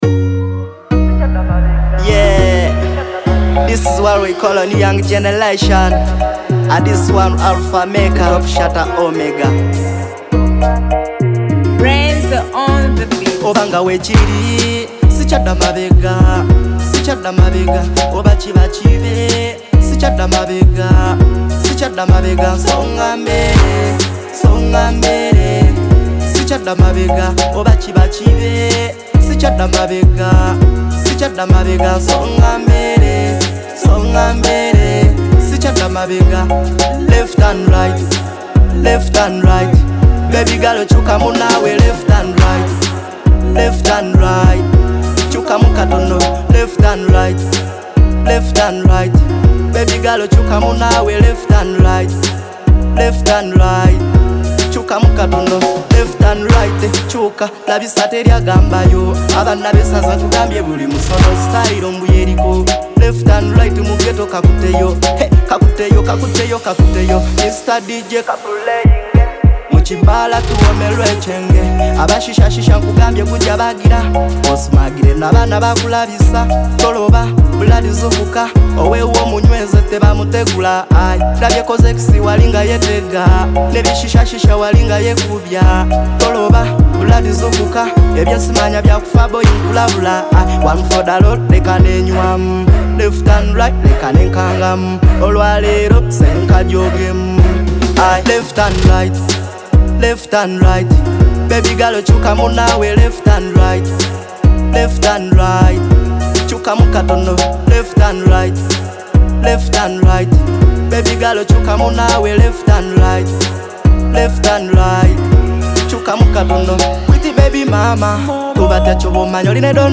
Sweet lyrics, good massage,,love song